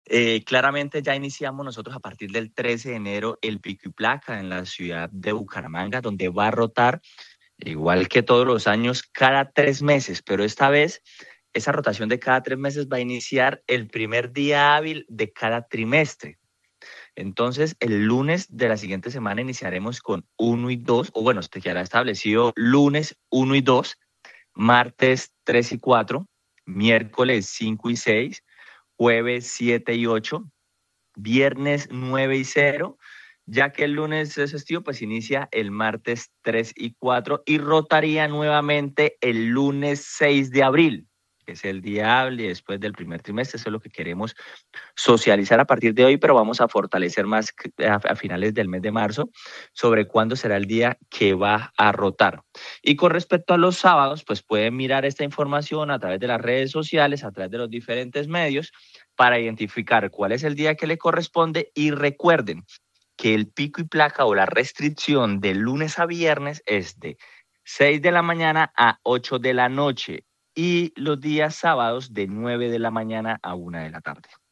Director de Tránsito de Bucaramanga, Jhair Manrique